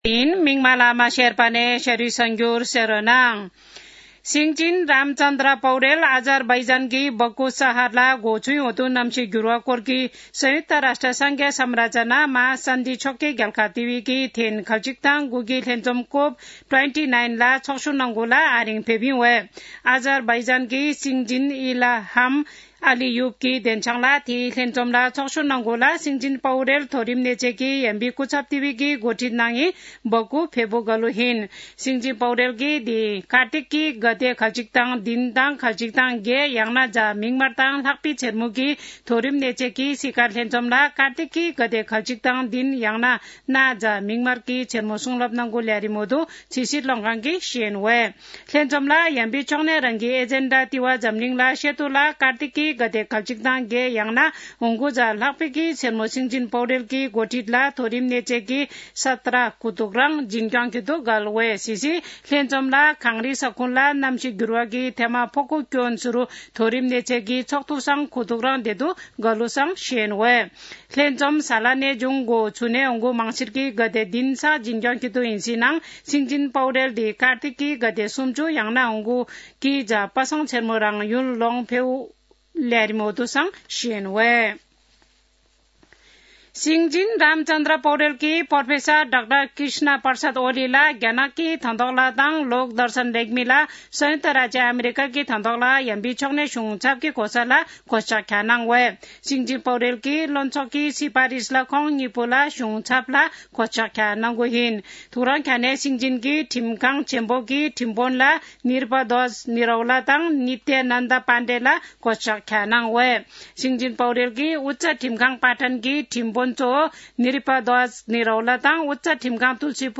शेर्पा भाषाको समाचार : २६ कार्तिक , २०८१